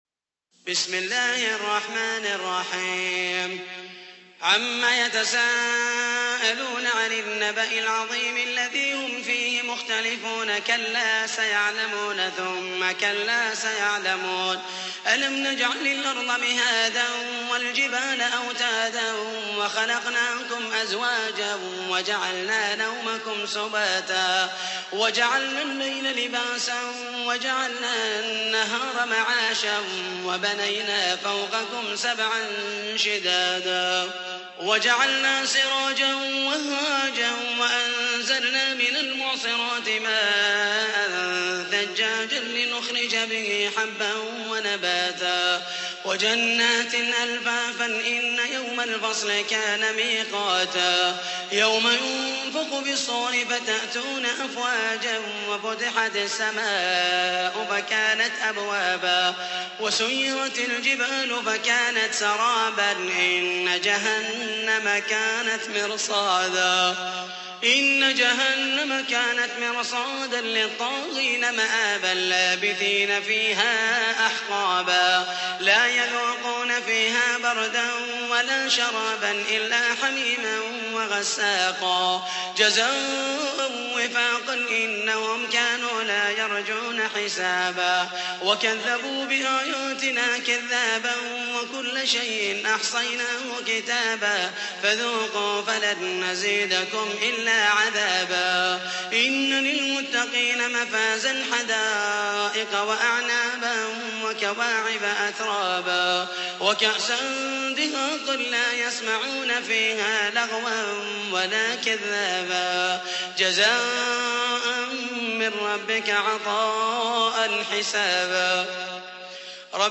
تحميل : 78. سورة النبأ / القارئ محمد المحيسني / القرآن الكريم / موقع يا حسين